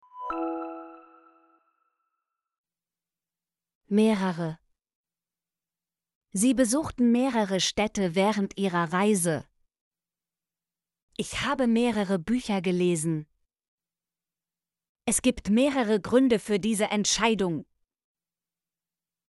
mehrere - Example Sentences & Pronunciation, German Frequency List